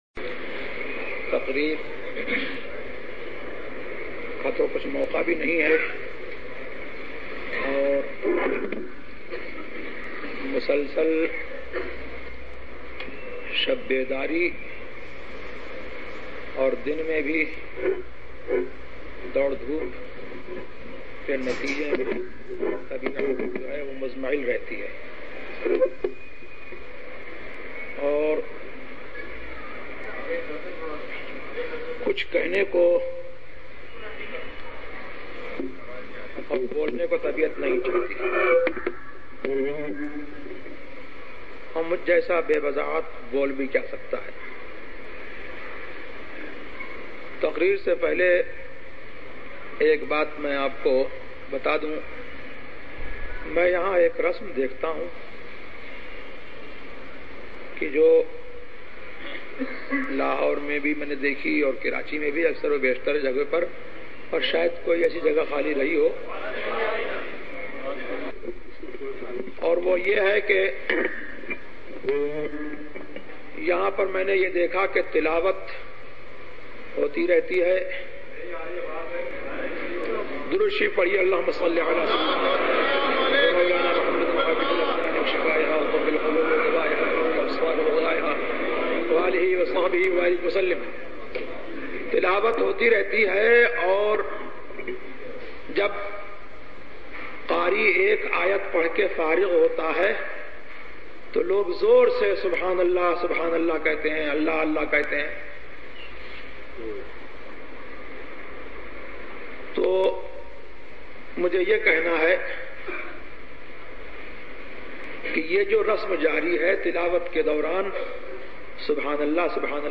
تقاریر